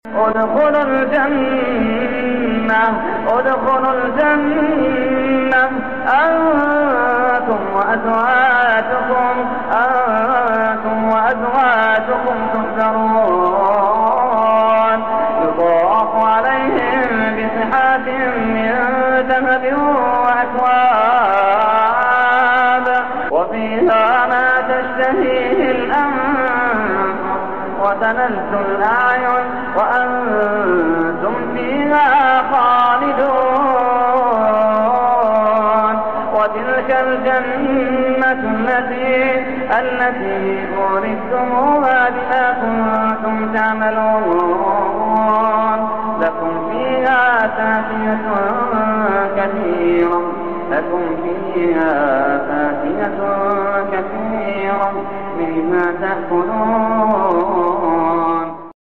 ماتيسر من سورة(الزخرف) بصوت الشيخ محمد اللحيدان❤